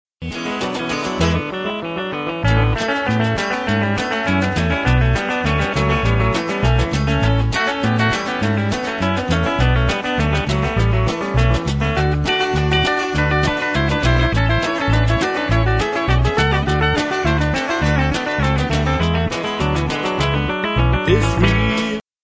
excerpt: guitar solo
country